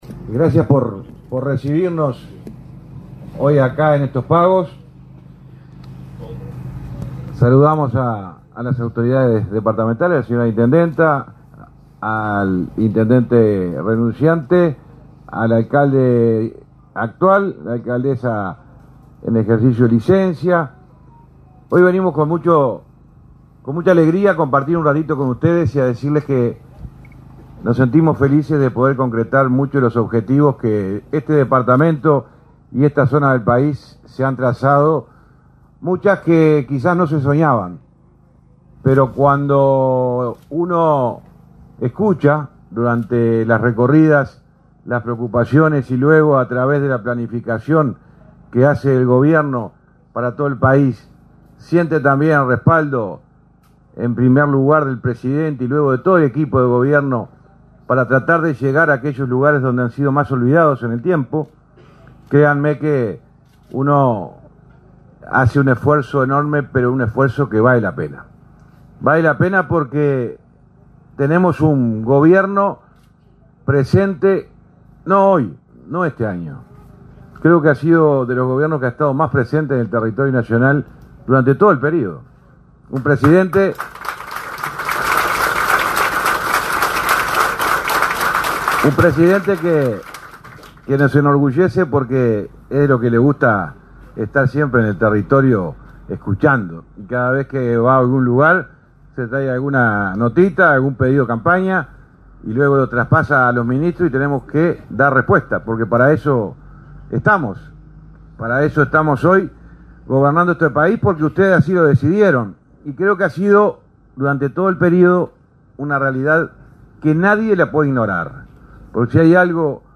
Palabras del titular del MTOP, José Luis Falero
Palabras del titular del MTOP, José Luis Falero 17/10/2024 Compartir Facebook X Copiar enlace WhatsApp LinkedIn Con la presencia del presidente de la República, Luis Lacalle Pou, este 17 de octubre, quedó inaugurada la obra en la ruta n.° 25, en el tramo entre Tres Bocas y Young, en el departamento de Río Negro. En el evento participó el titular del Ministerio de Transporte y Obras Públicas (MTOP), José Luis Falero.
Falero Oratoria.mp3